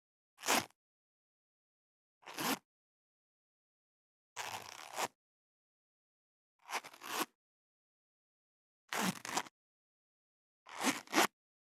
27.ジッパーを開ける【無料効果音】
ASMRジッパー効果音
ASMR